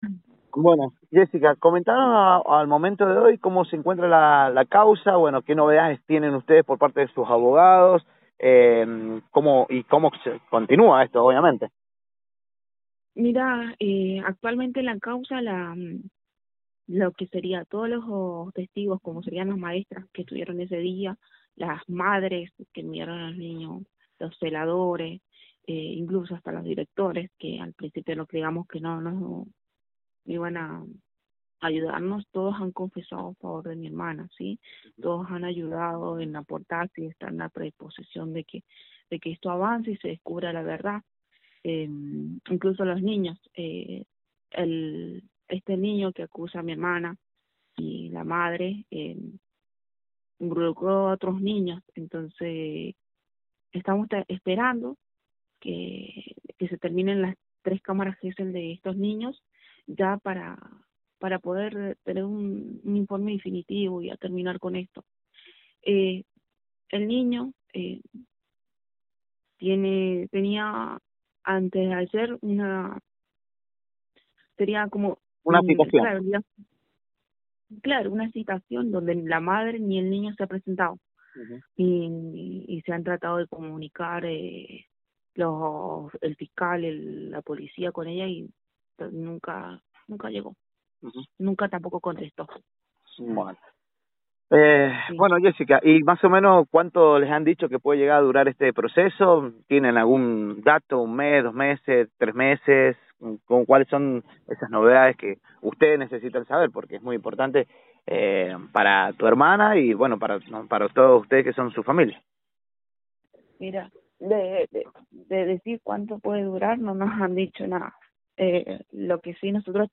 En el segundo tramo de la entrevista, la joven comenta como está la causa, sobre los testigos que apoyan a la docente y que se esperan los resultados de las Cámaras Gesell a los compañeritos del niño.